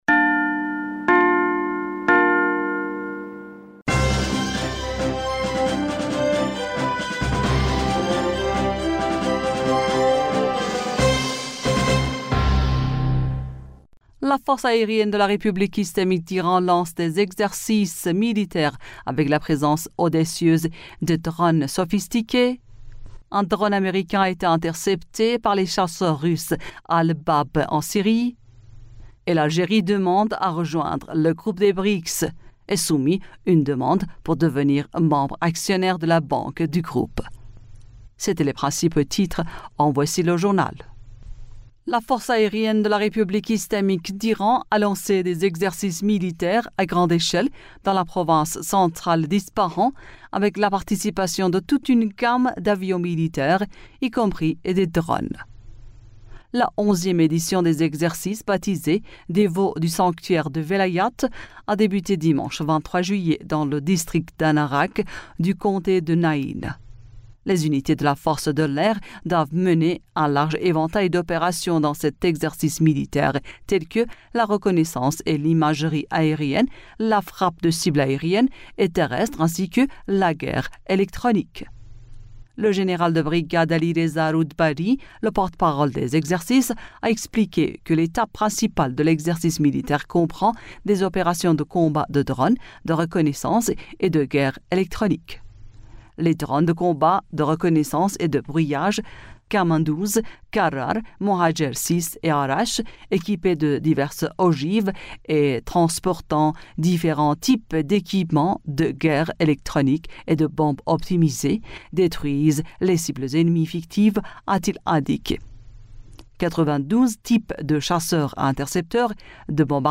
Bulletin d'information du 24 Juillet 2023